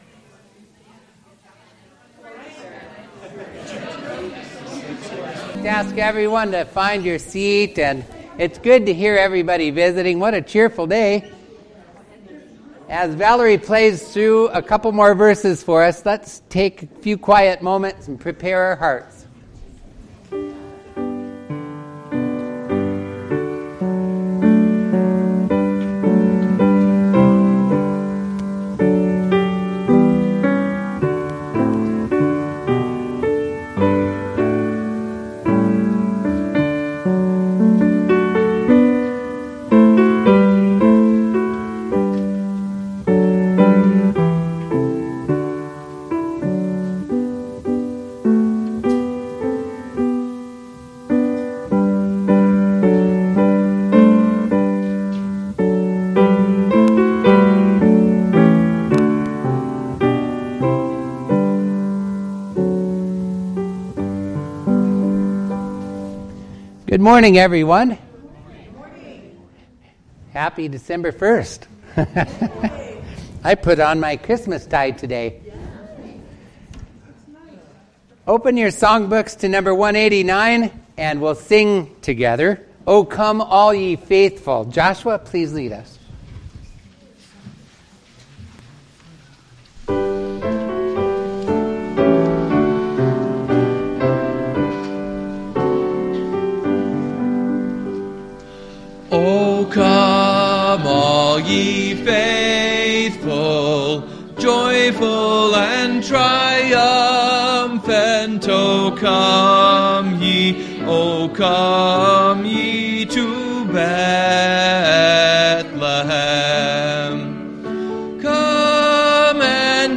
This Sunday’s Sermon: